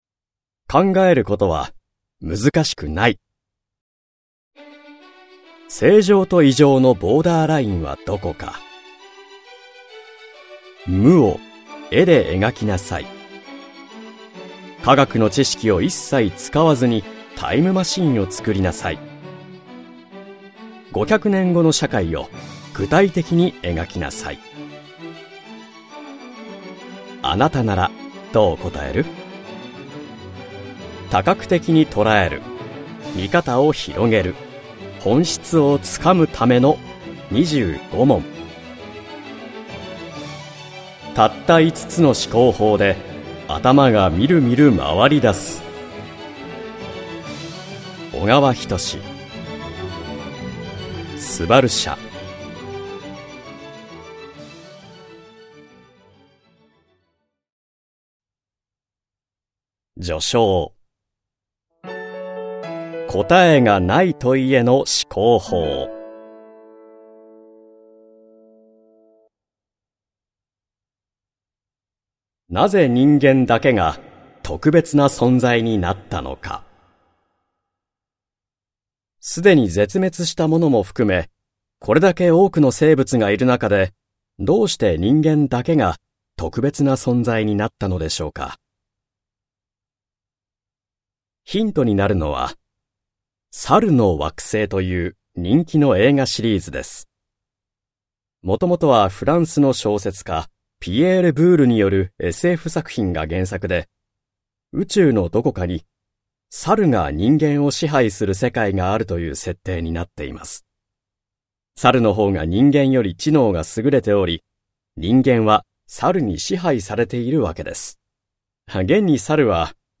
[オーディオブックCD] たった5つの思考法で頭がみるみる回り出す！